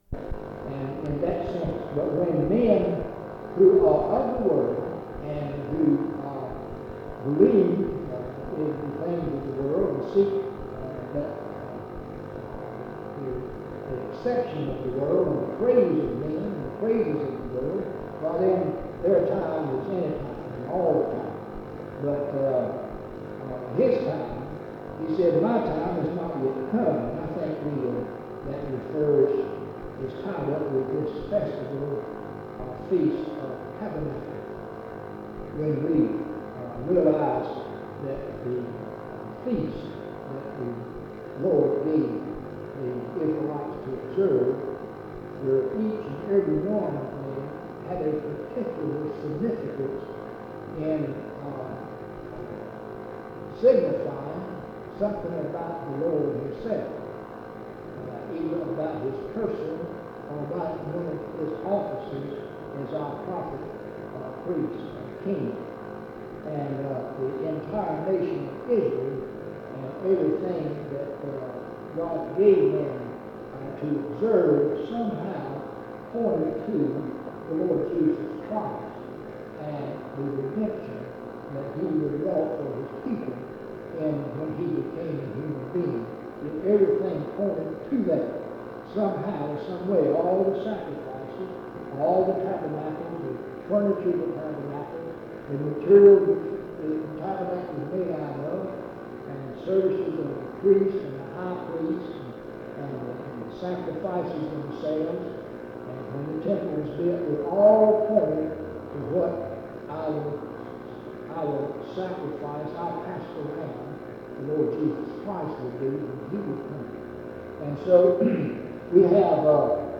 En Collection: Reidsville/Lindsey Street Primitive Baptist Church audio recordings Miniatura Título Fecha de subida Visibilidad Acciones PBHLA-ACC.001_009-B-01.wav 2026-02-12 Descargar PBHLA-ACC.001_009-A-01.wav 2026-02-12 Descargar